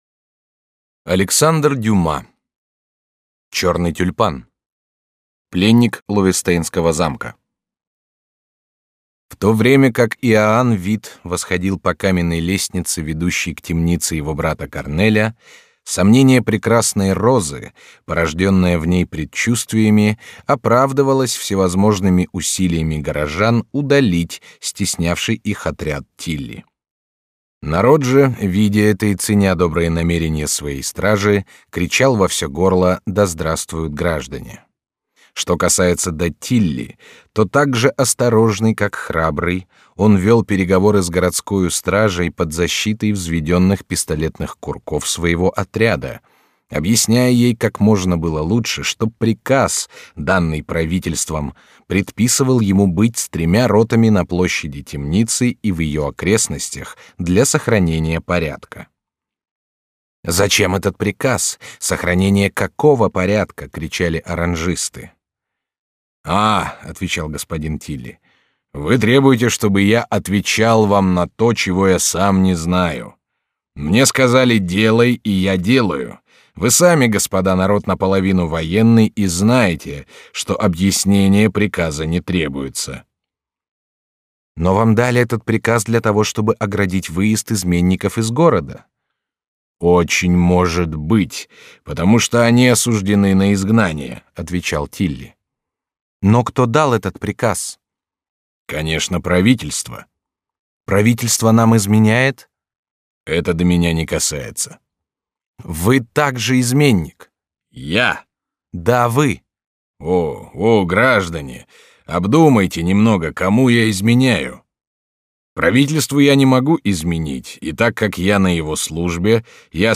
Аудиокнига Черный тюльпан | Библиотека аудиокниг